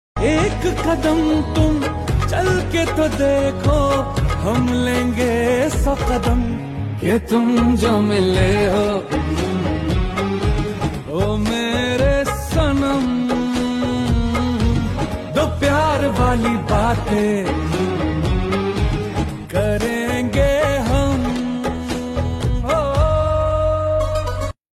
Hindi Songs
• Simple and Lofi sound
• High-quality audio
• Crisp and clear sound